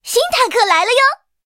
M4A3E2小飞象建造完成提醒语音.OGG